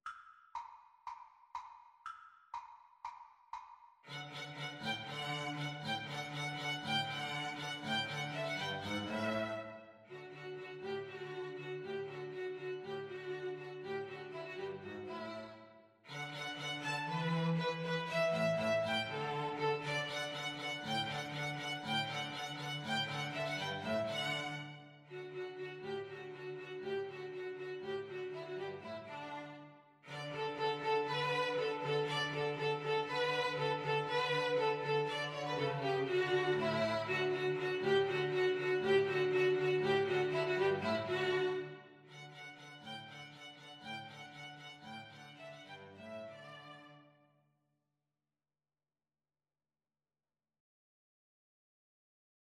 Allegro (View more music marked Allegro)
String trio  (View more Easy String trio Music)